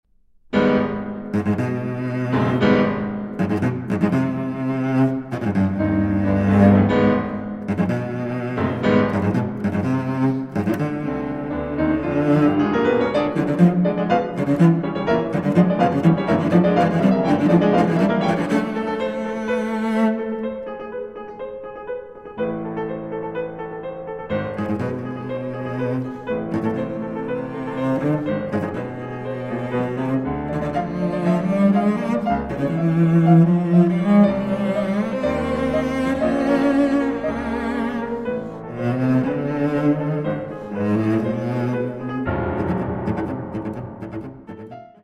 are possessed of darker, cinematic inspiration